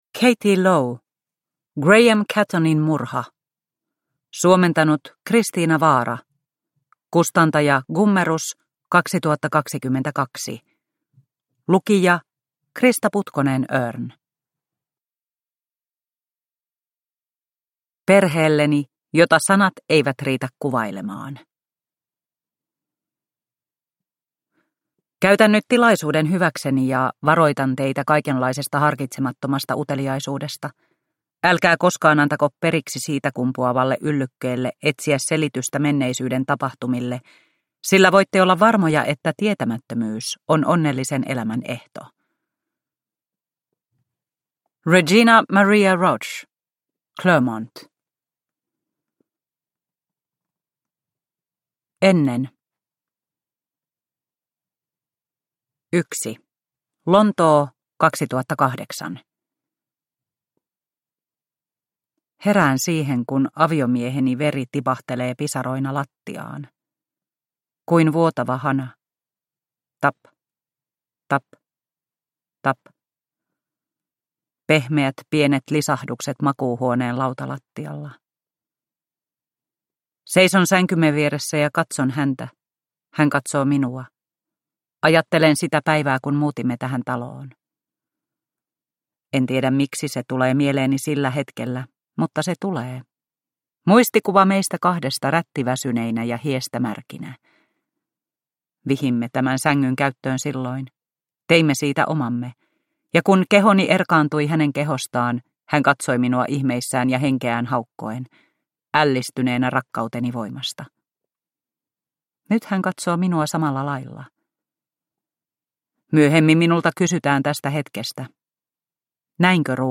Graham Cattonin murha – Ljudbok – Laddas ner